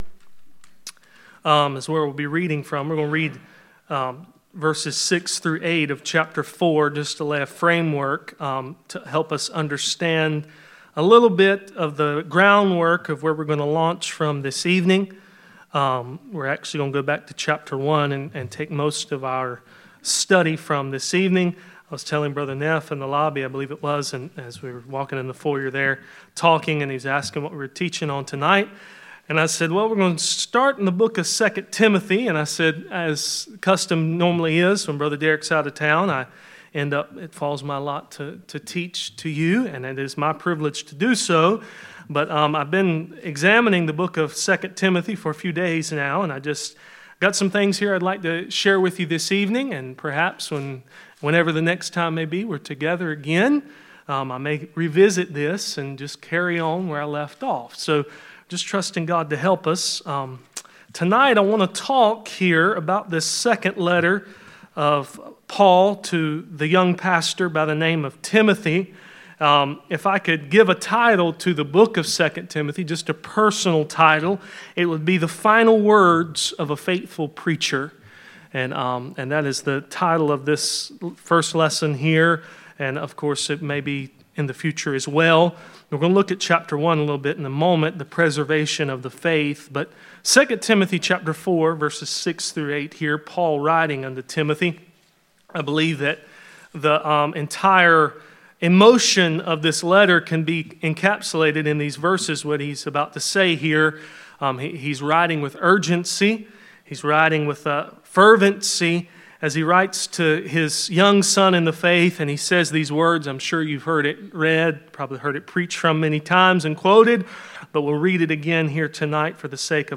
Passage: 2 Timothy 4:6-8 Service Type: Midweek Meeting